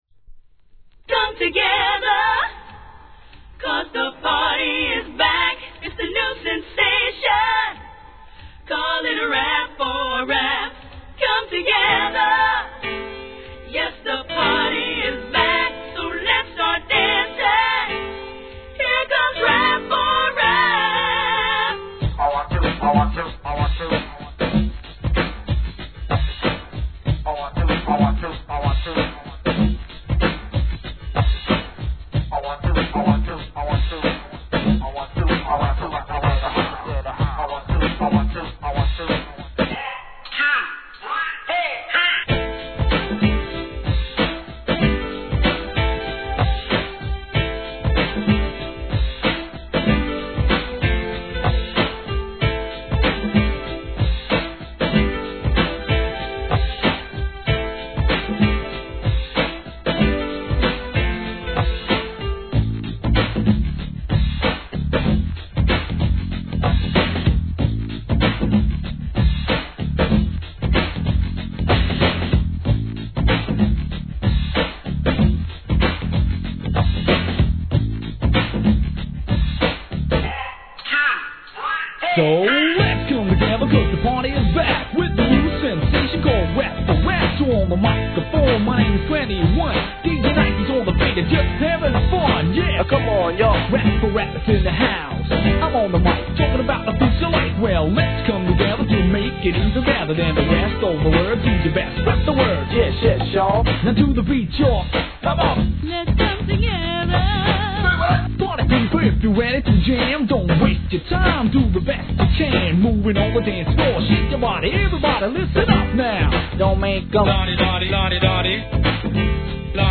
HIP HOP/R&B
コテコテのグランドビートに、分かりやすくコーラスを絡める王道RAP!